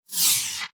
Select Scifi Tab 5.wav